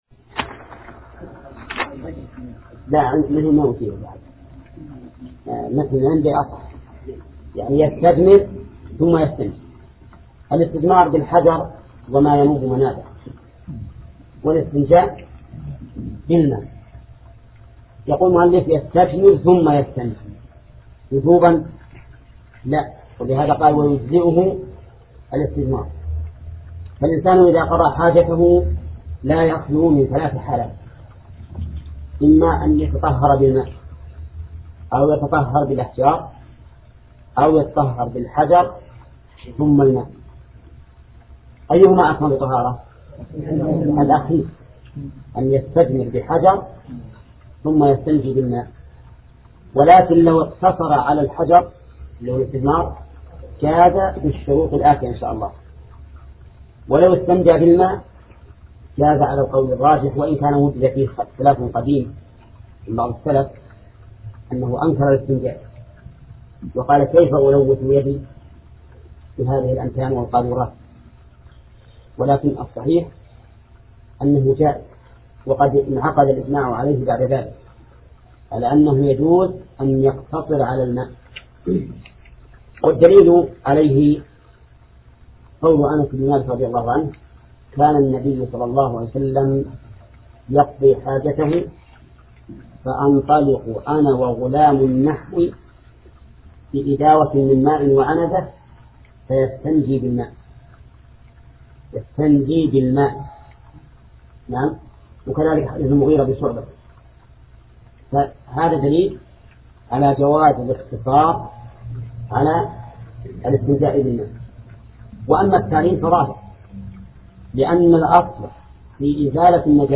درس (7): تتمة باب الاستنجاء